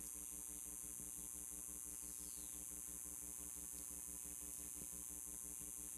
Spring Brook Township, Pennsylvania